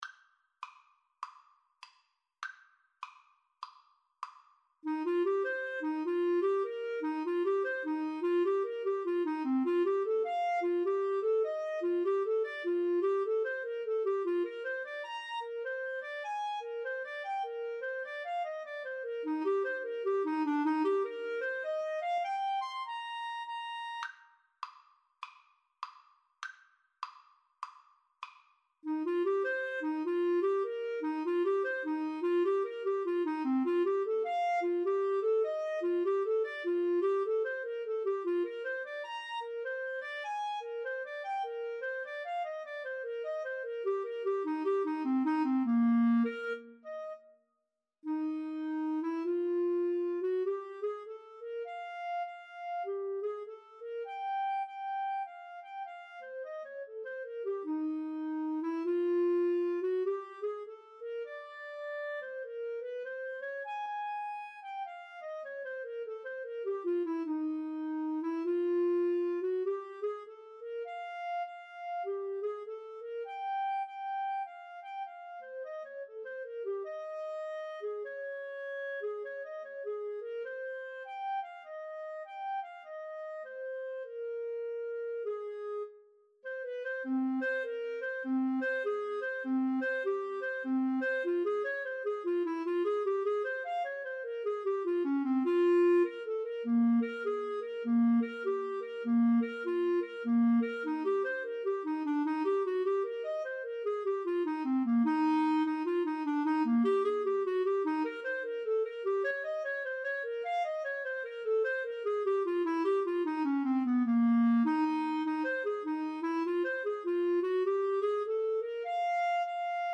Eb major (Sounding Pitch) (View more Eb major Music for Clarinet-French Horn Duet )
Lightly = c. 100
4/4 (View more 4/4 Music)